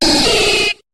Cri de Capumain dans Pokémon HOME.